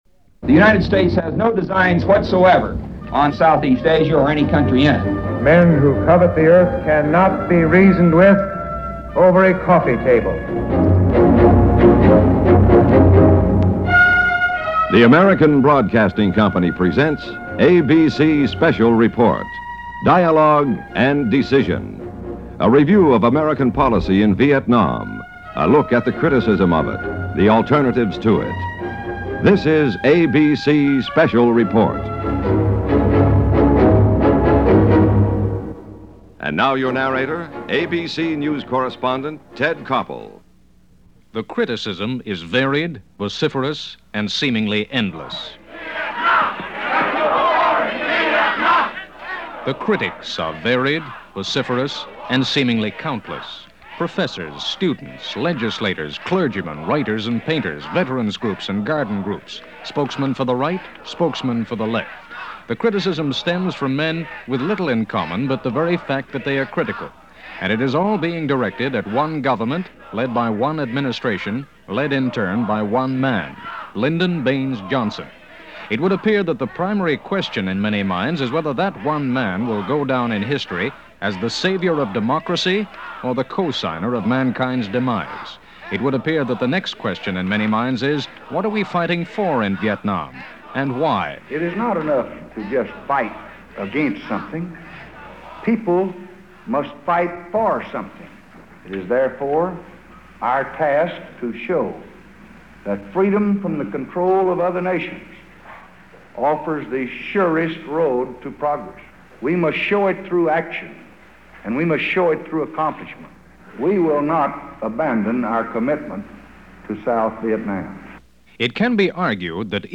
ABC News Special Report
Here is that ABC Radio News Special Report from July 2, 1965 as narrated by a very young Ted Koppel.